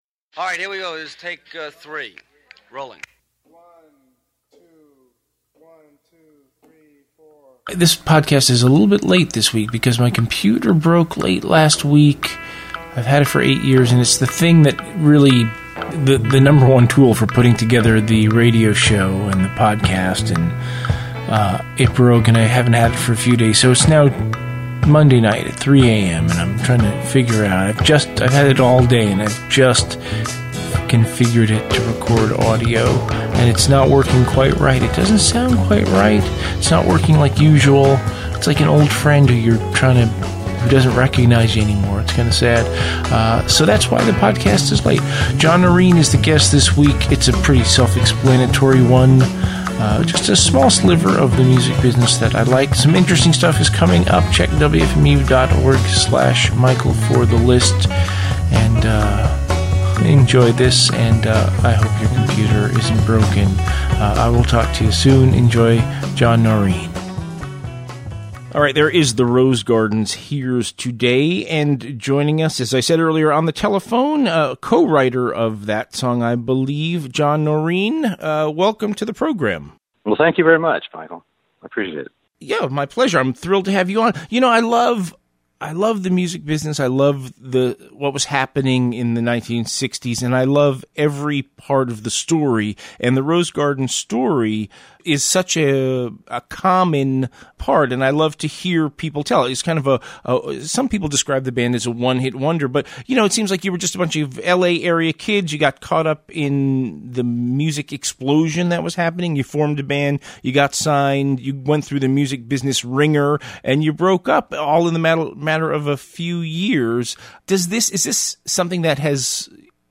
"Interview"Rose Garden